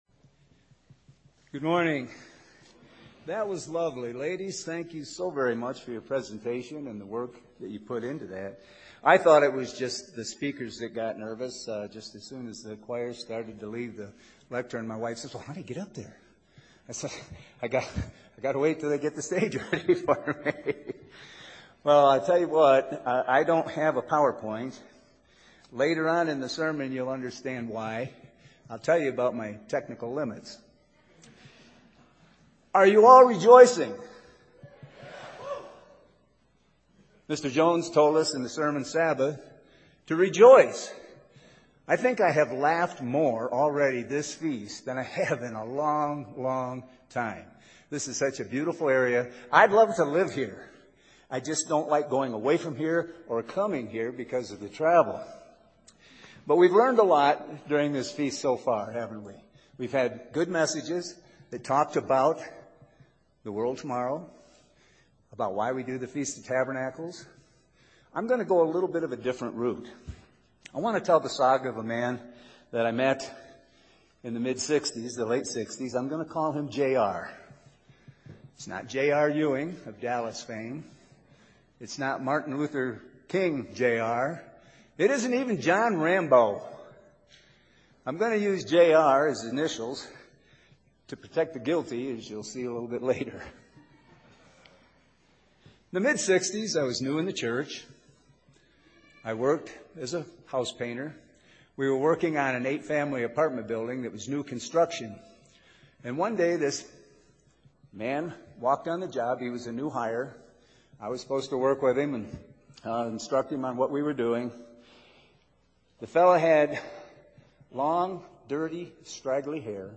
This sermon was given at the Estes Park, Colorado 2022 Feast site.